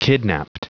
Prononciation du mot kidnaped en anglais (fichier audio)
Prononciation du mot : kidnaped